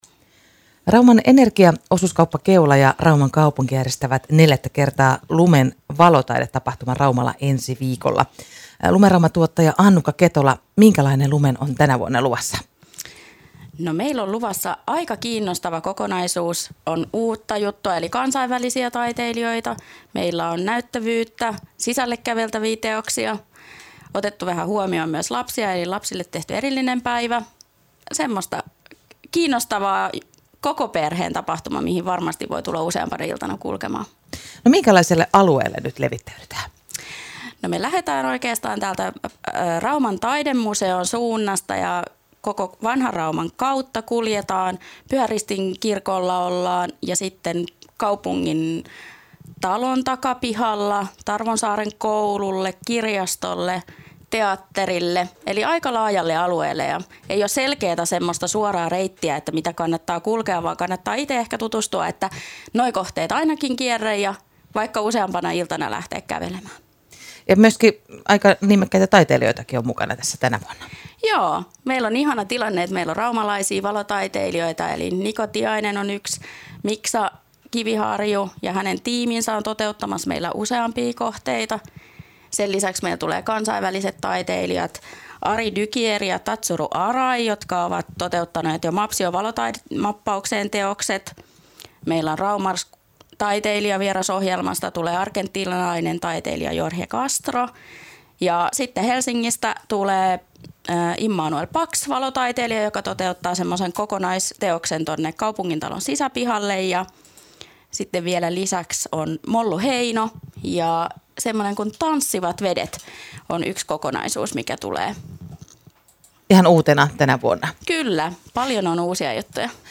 Neljättä kertaa järjestettävä Lumen valotaidetapahtuma kutsuu kävelemään kaupungin keskustaan ensi viikolla iltaisin.  Haastattelussa